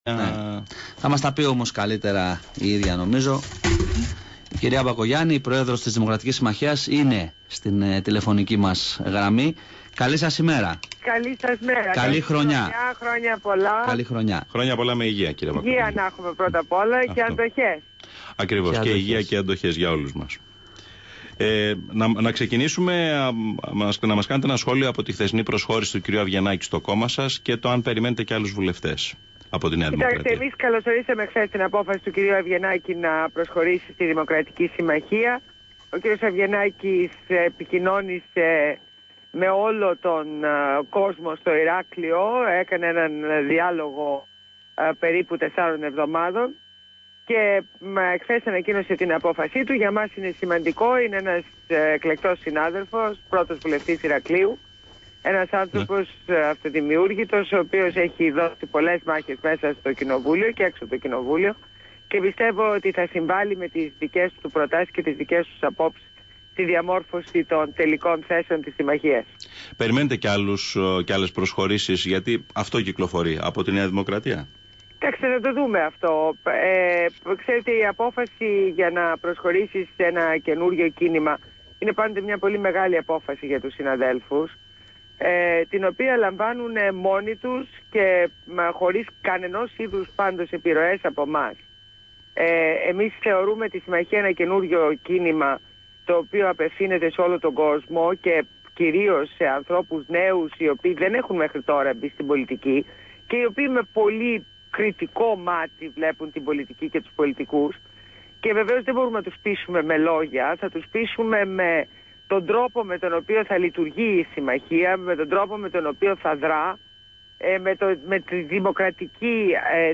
Συνέντευξη Ντόρας Μπακογιάννη στο ραδιόφωνο Flash 96.0 | Πρόεδρος
Ακούστε τη συνέντευξη που έδωσε η πρόεδρος του κινήματος στο ραδιόφωνο Flash 96.0.